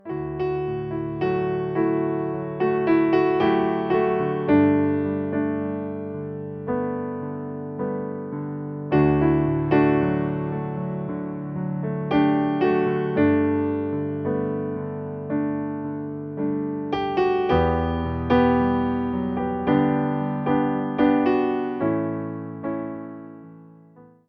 Wersja demonstracyjna:
59 BPM
D – dur